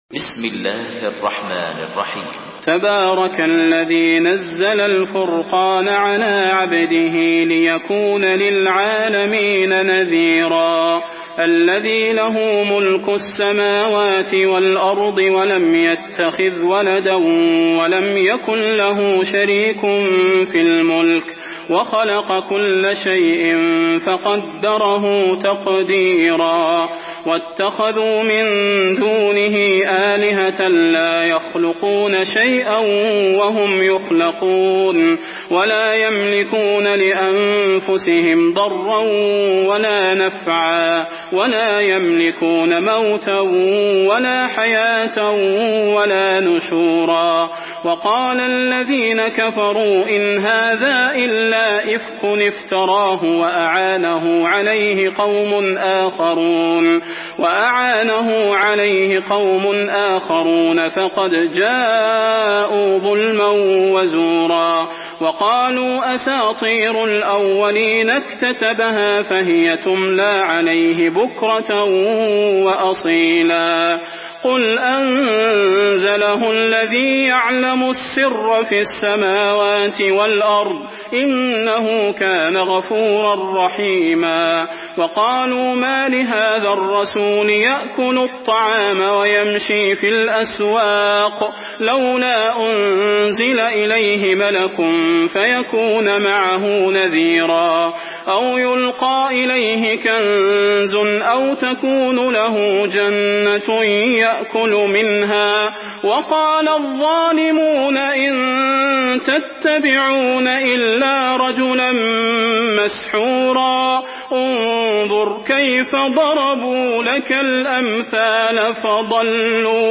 Surah Furkan MP3 in the Voice of Salah Al Budair in Hafs Narration
Murattal Hafs An Asim